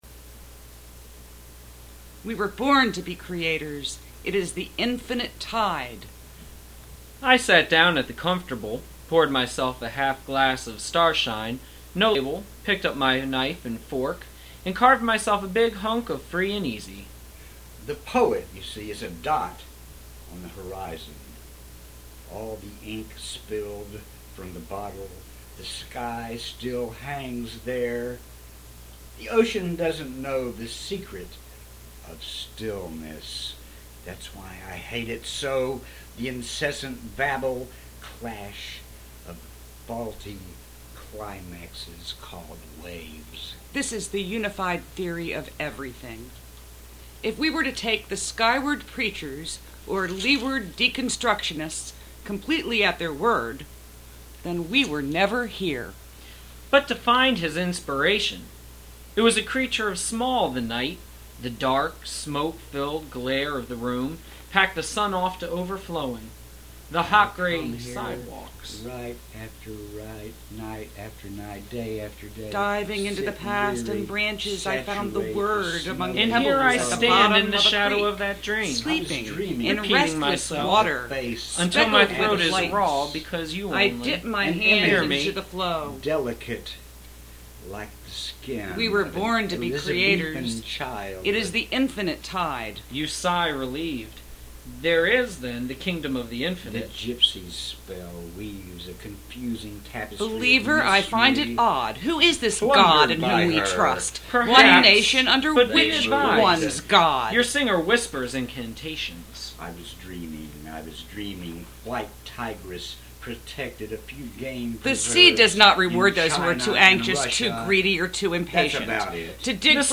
Obviously, it's totally unrehearsed and random but it was a collaborative creative effort which was a lotta fun.